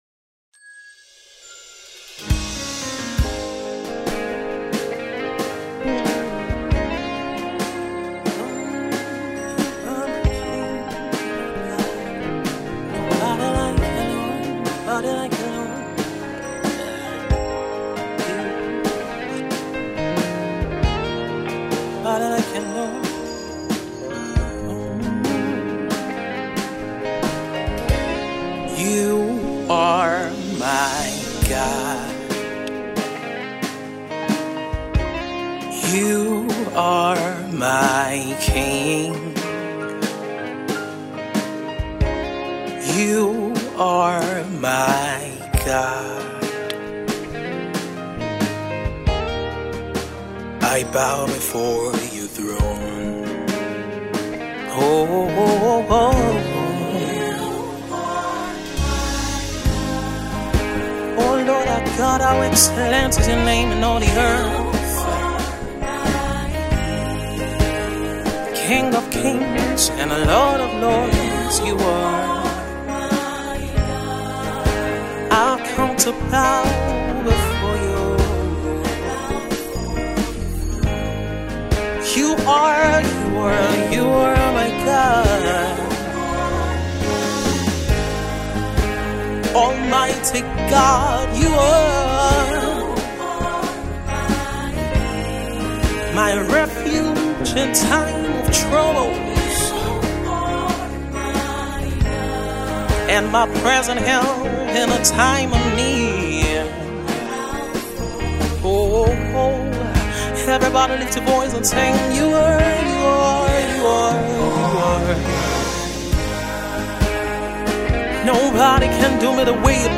soulful track
full of Spirit and excellent musicianship